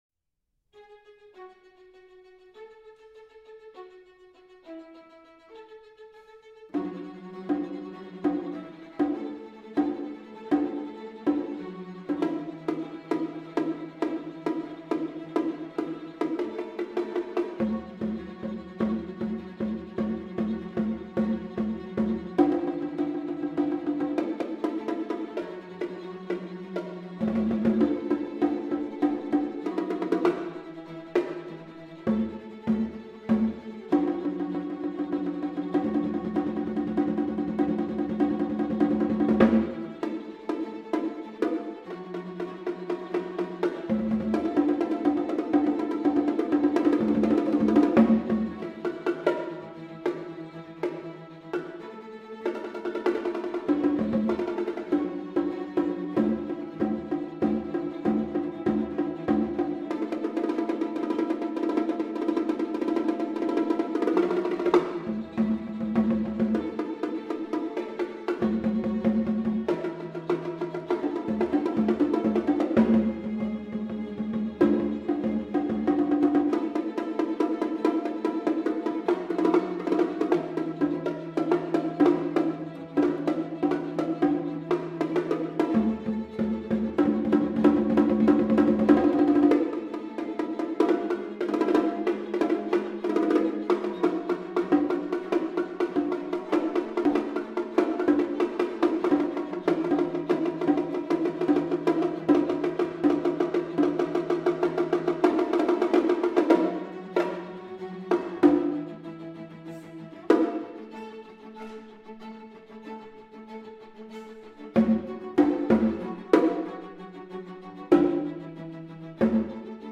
percussion instrumentation: two congas, one pair of bongos